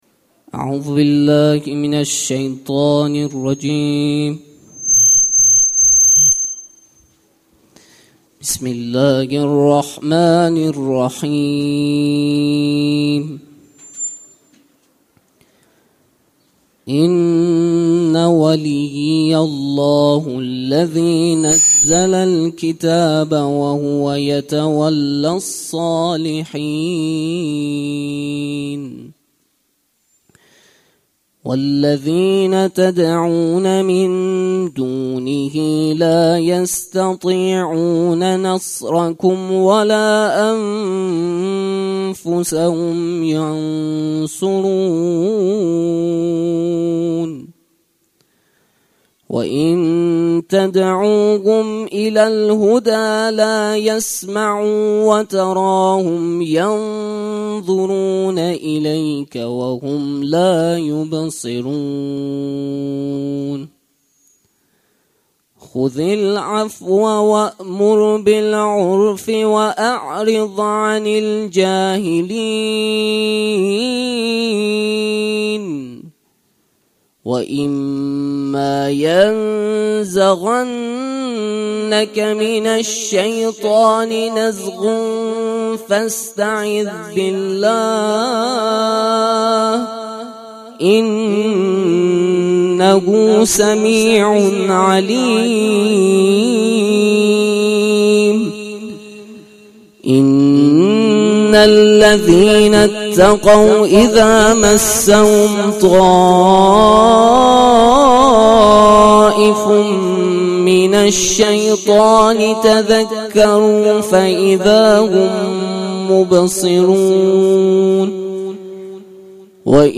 در این بخش می توانید فایل صوتی بخش های مختلف “یکصد و هفتاد و ششمین کرسی تلاوت و تفسیر قرآن کریم” شهرستان علی آباد کتول که در تاریخ ۰۸/خرداد ماه/۱۳۹۷ برگزار شد را دریافت نمایید.
ترتیل صفحه شریفه ۱۷۶ قرآن کریم